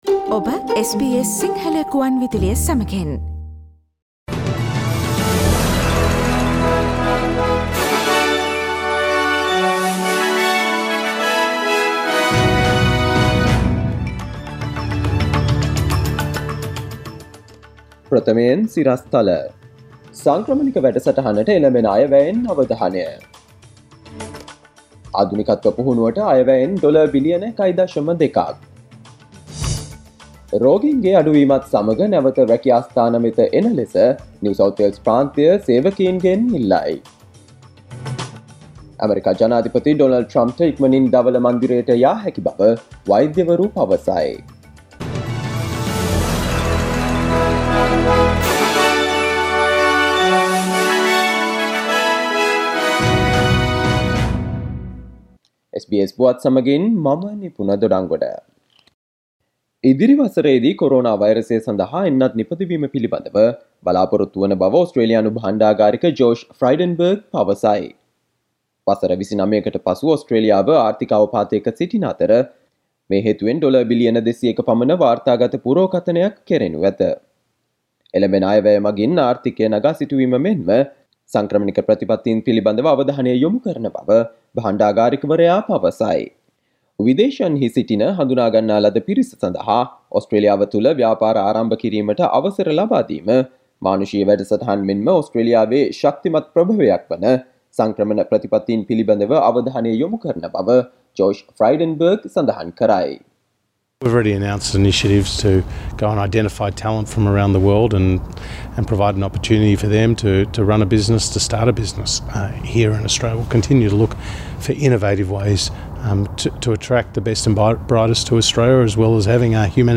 Daily News bulletin of SBS Sinhala Service: Monday 05 October 2020